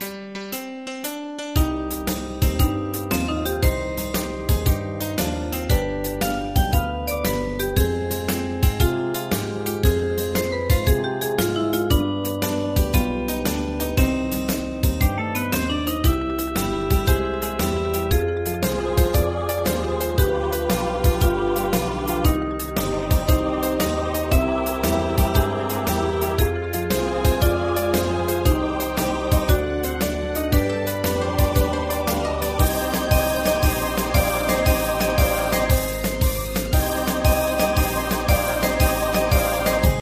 Ensemble musical score and practice for data.
Tags: Japanese , Kayokyoku Enka .